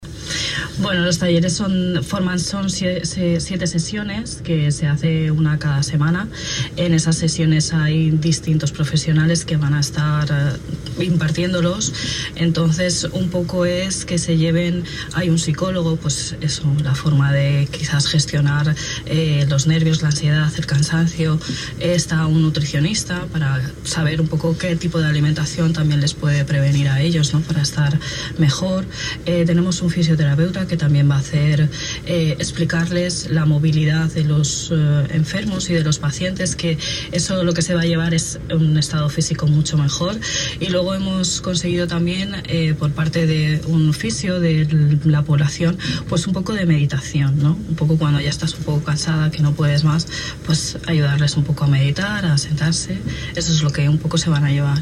Entrevistas Sanidad